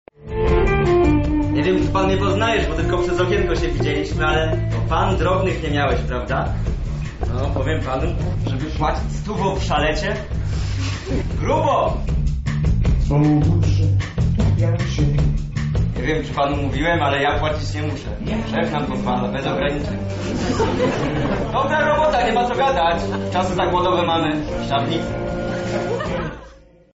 Wczoraj w Chatce Żaka odbyła się impreza charytatywna.